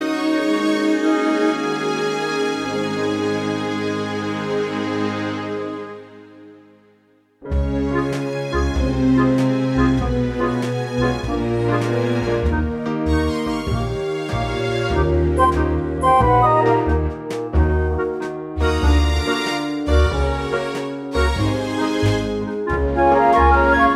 Film Version Soundtracks 2:51 Buy £1.50